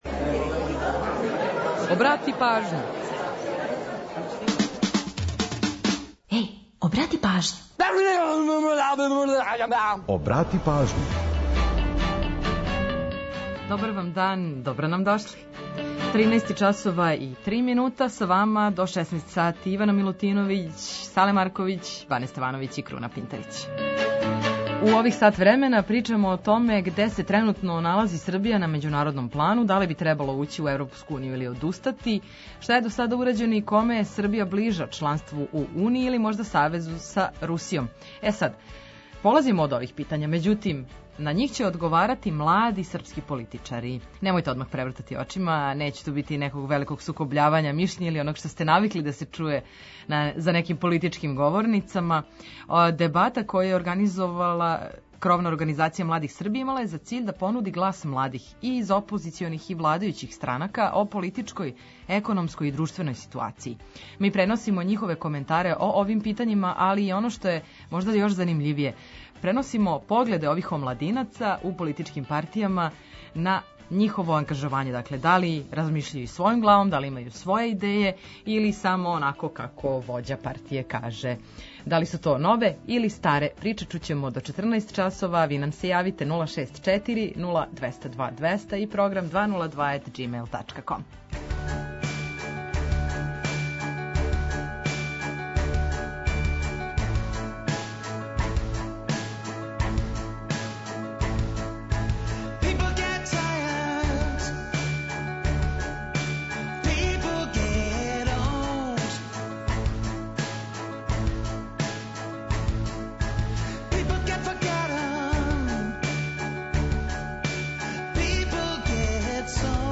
Одговоре на ова питања покушали су да дају млади српски политичари. Дебата одржана крајем фебруара је имала за циљ да понуди глас младих из опозиционих и владајућих странака о политичкој, економској и друштвеној ситуацији.